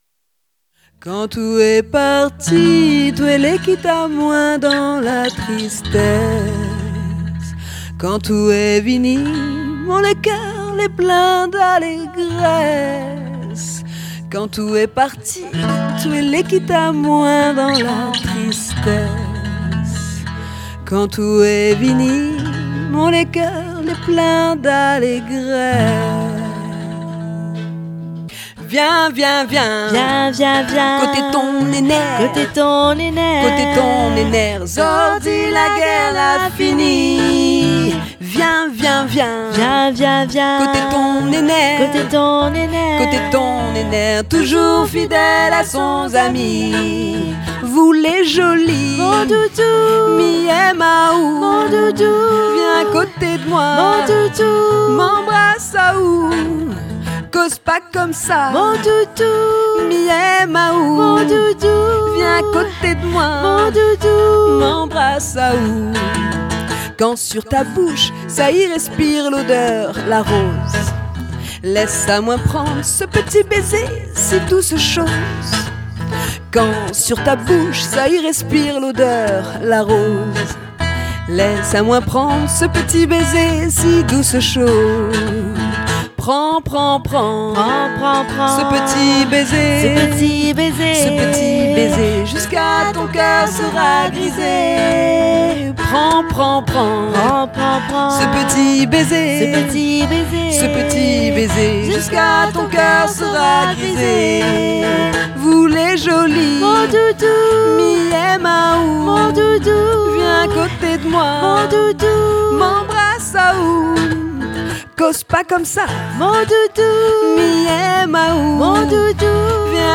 Entrevue avec ces deux derniers.